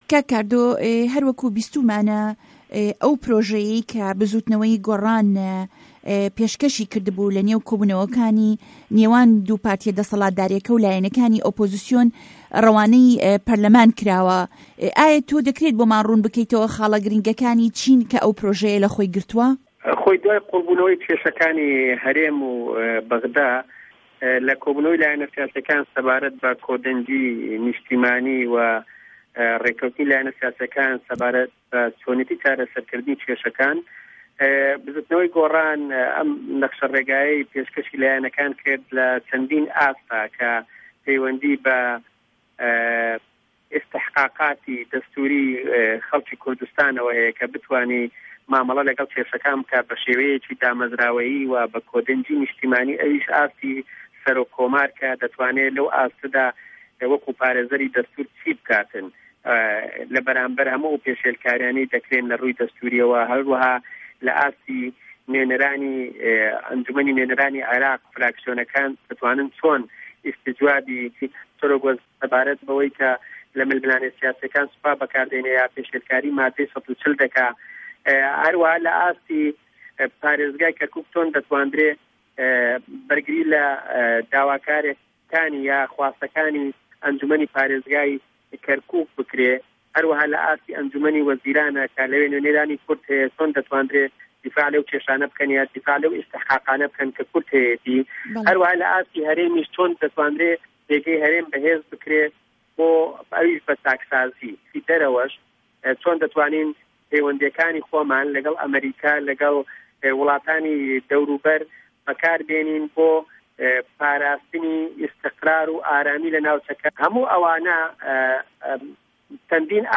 گفتوگۆ ڵه‌گه‌ڵ کاردۆ محه‌مه‌د 23 ی 1ی ساڵی 2013